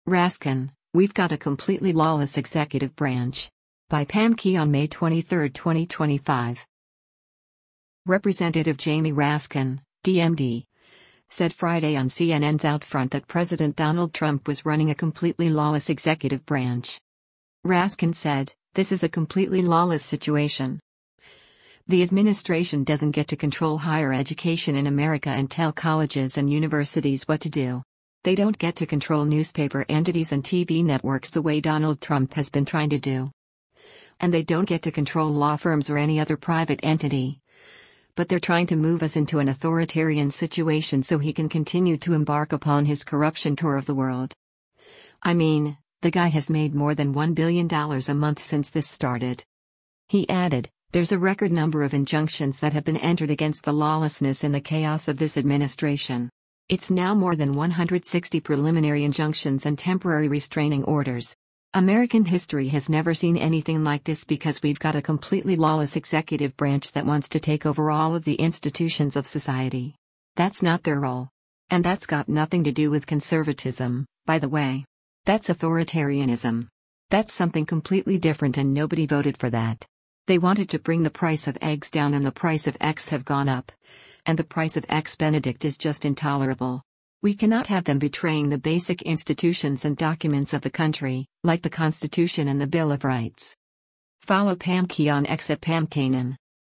Representative Jamie Raskin (D-MD) said Friday on CNN’s “OutFront” that President Donald Trump was running a “completely lawless executive branch.”